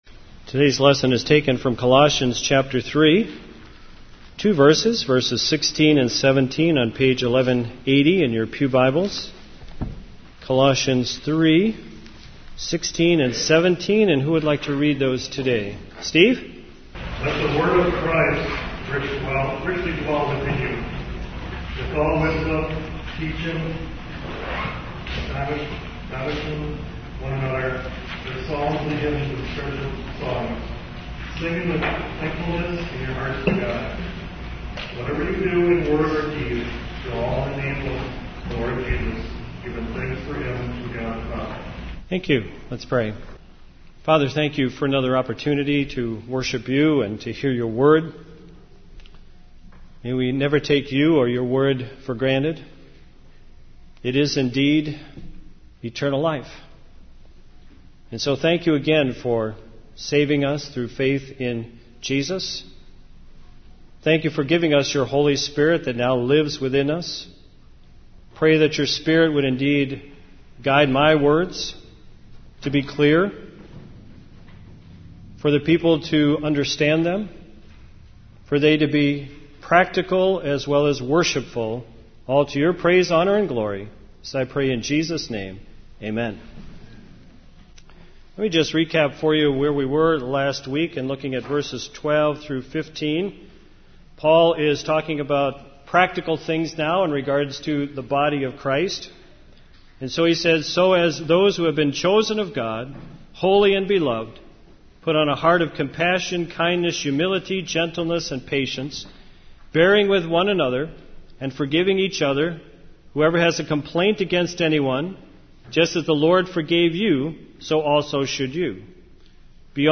Sermons | Hartford Bible Church